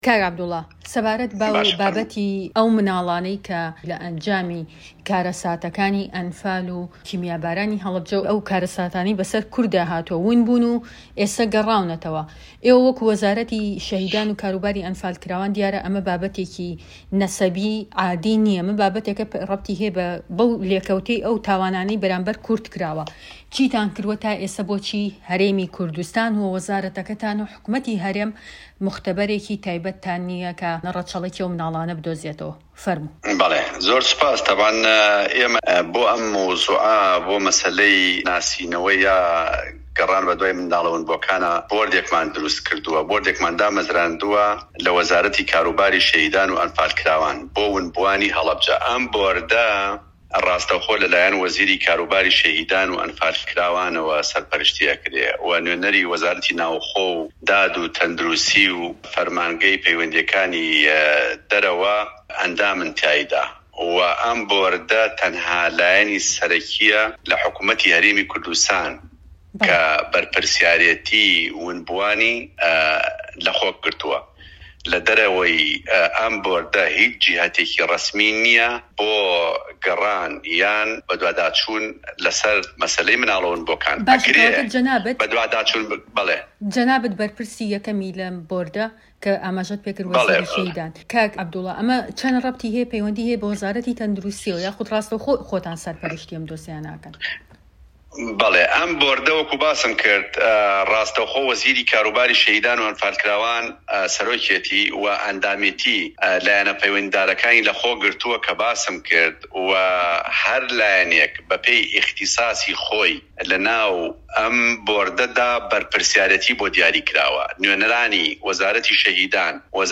وتووێژ لەگەڵ عەبدوڵای حاجی مەحمود